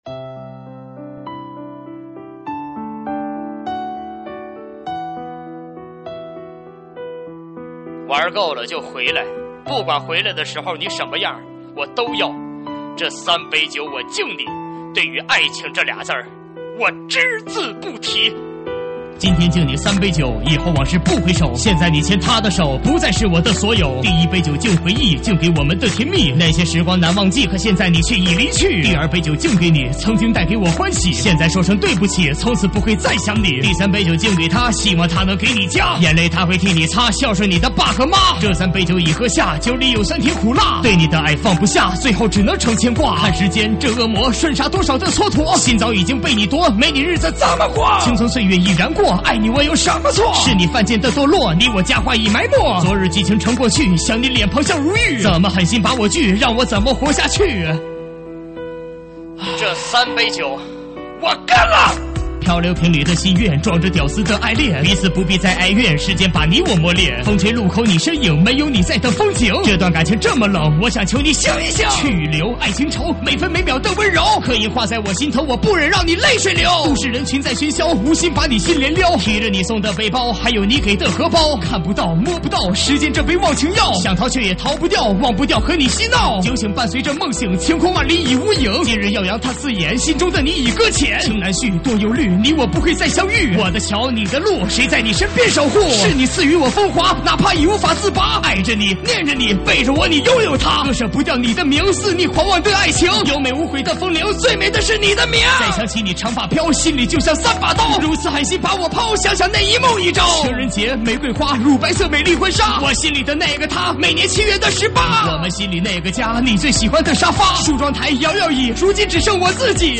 喊麦现场